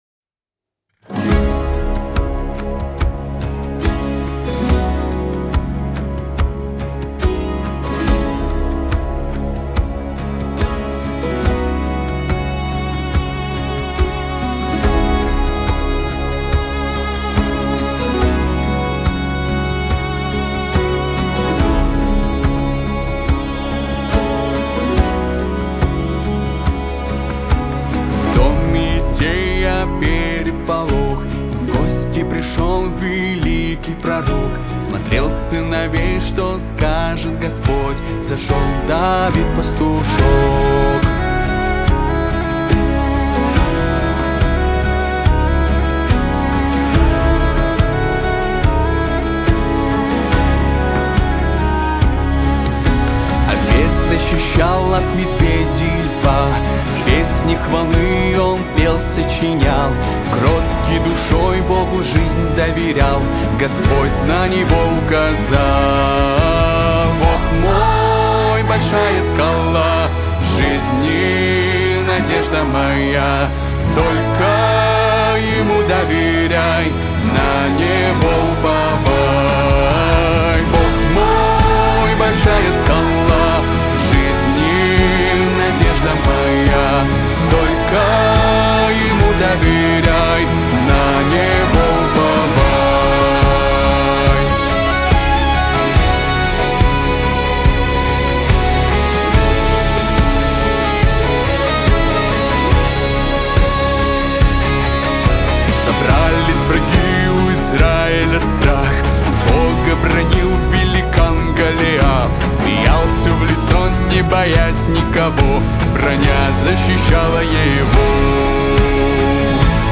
882 просмотра 1901 прослушиваний 146 скачиваний BPM: 70